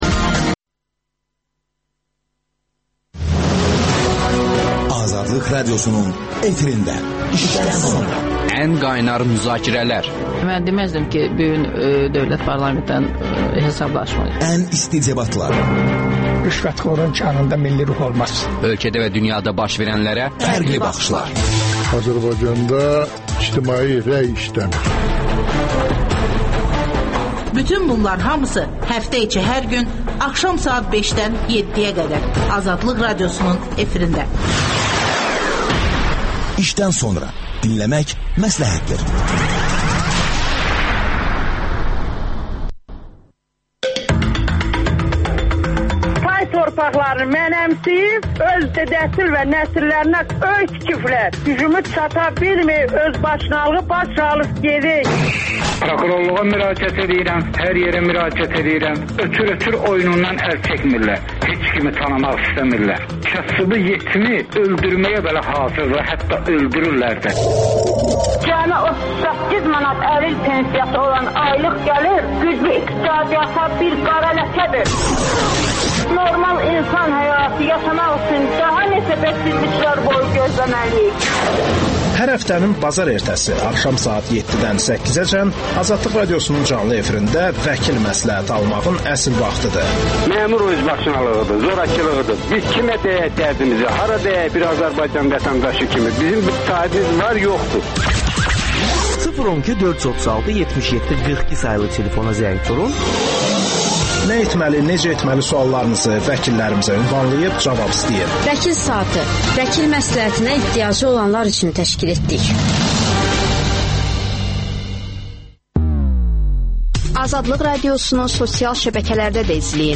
AXCP sədri Əli Kərimli ölkədə baş verən son siyasi proseslər barədə sualları canlı efirdə cavablayır.